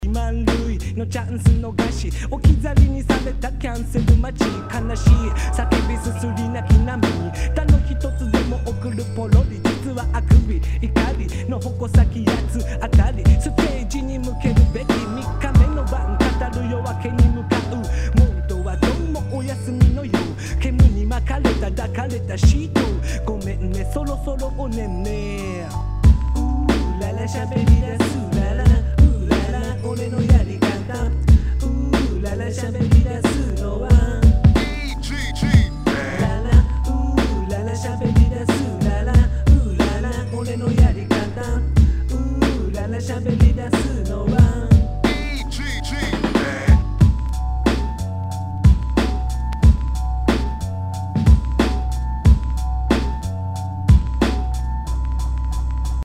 HIPHOP/R&B
ナイス！Jヒップホップ！！